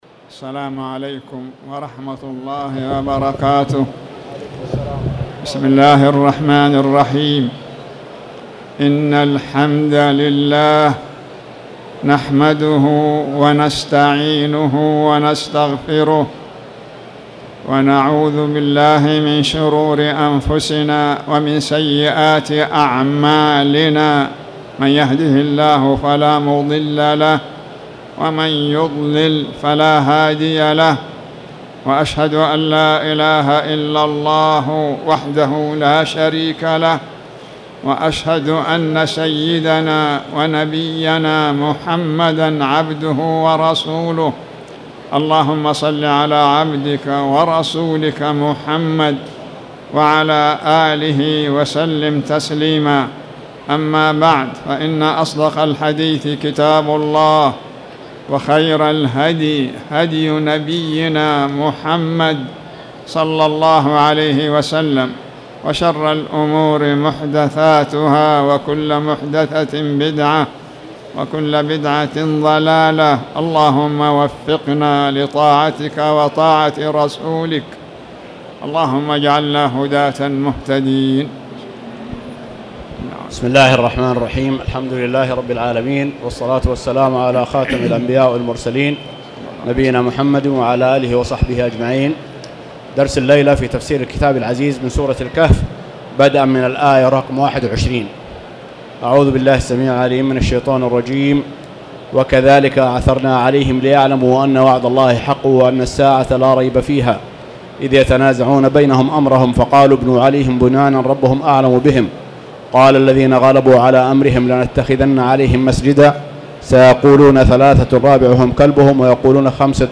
تاريخ النشر ٥ ذو القعدة ١٤٣٧ هـ المكان: المسجد الحرام الشيخ